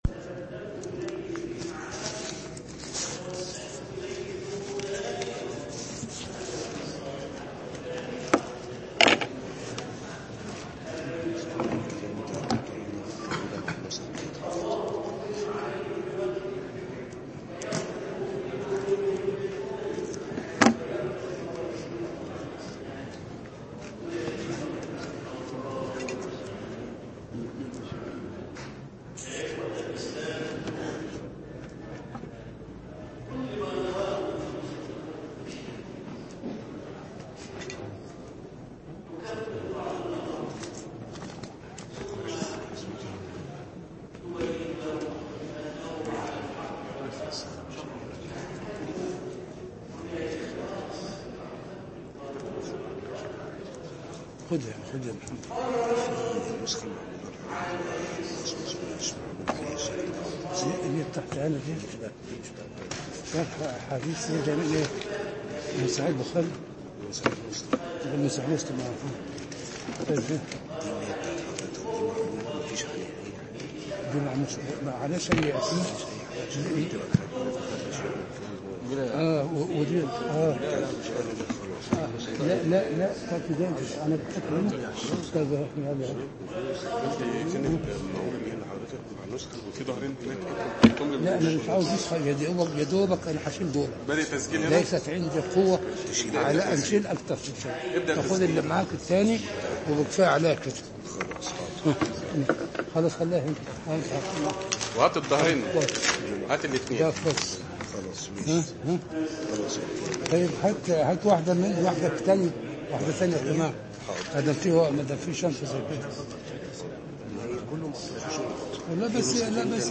عنوان المادة الدرس الحادي والثلاثون (شرح كتاب أسرار البلاغة) تاريخ التحميل الثلاثاء 20 سبتمبر 2016 مـ حجم المادة 22.64 ميجا بايت عدد الزيارات 576 زيارة عدد مرات الحفظ 199 مرة إستماع المادة حفظ المادة اضف تعليقك أرسل لصديق